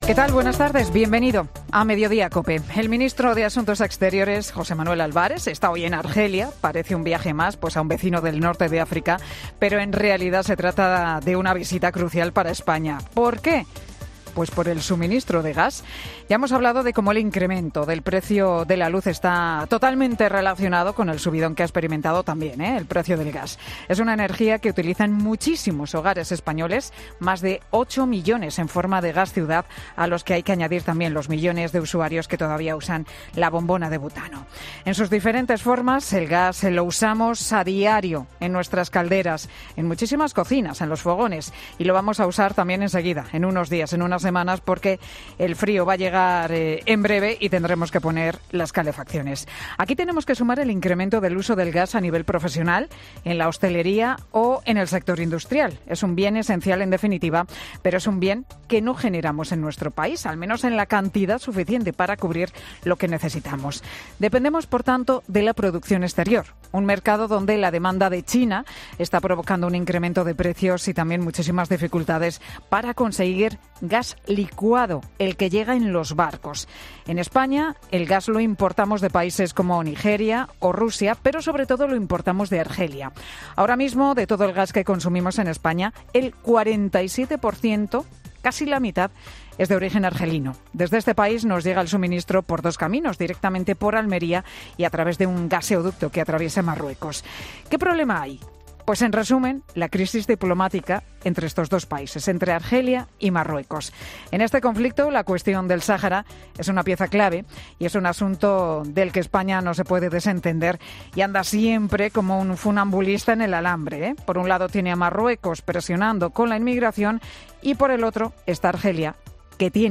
Monólogo de Pilar García Muñiz
El monólogo de Pilar García Muñiz en 'Mediodía COPE'